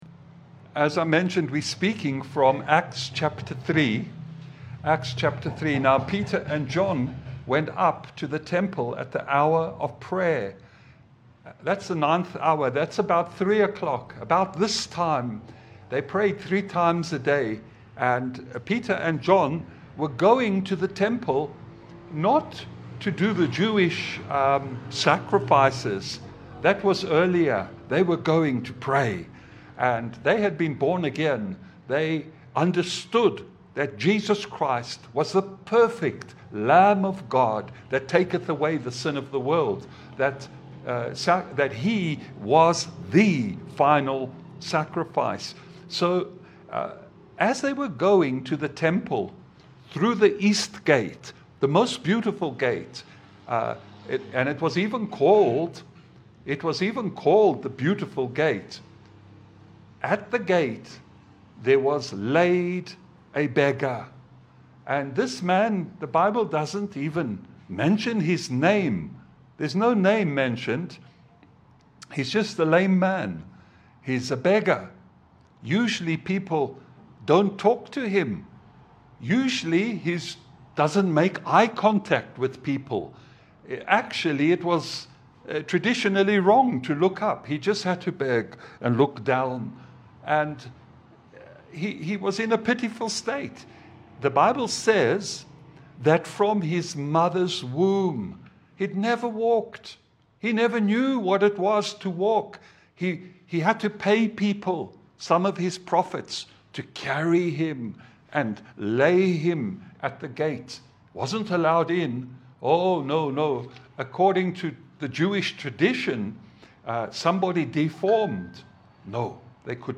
Acts 3:1 Service Type: Gospel Service « Seek the Things Above